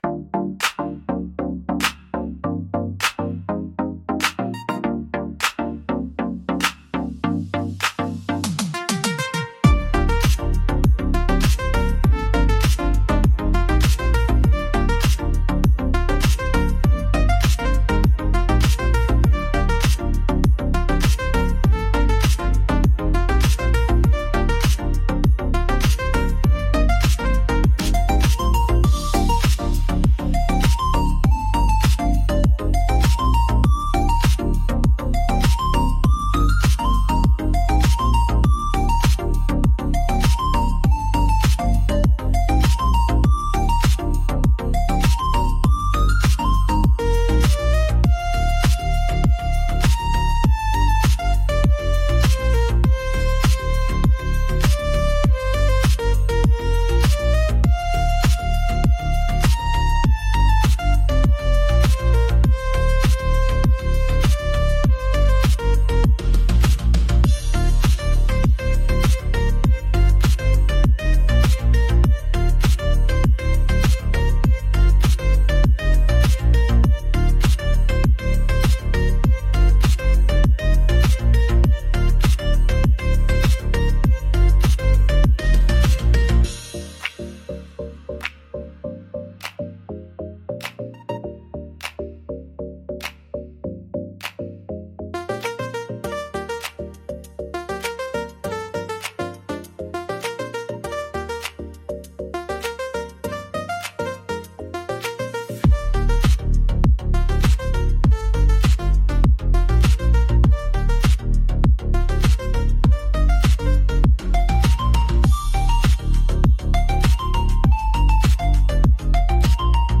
B♭ Major – 100 BPM
Chill
Electronic
Pop